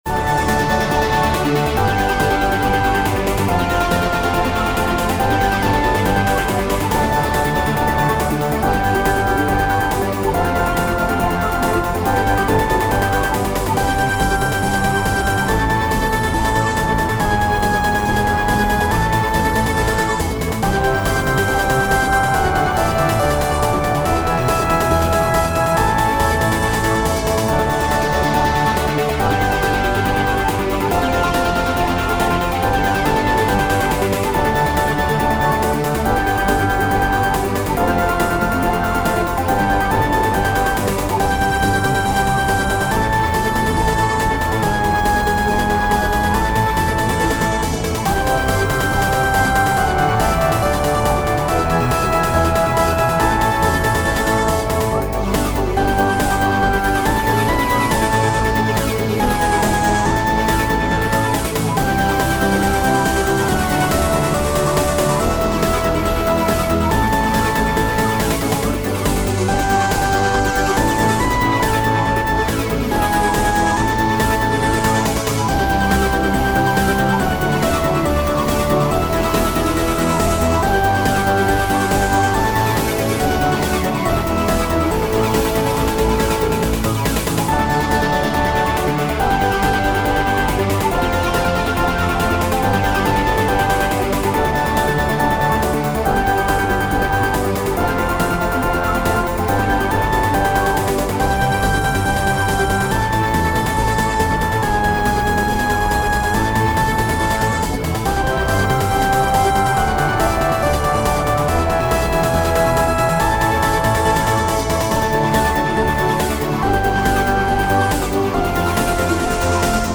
midi-demo 3